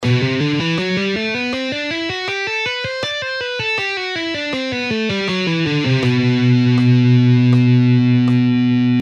Legato in B Phrygian Scale:
Half Speed:
1.-Legato-Exercise-In-B-Phyrigian-Scale-Half-Speed.mp3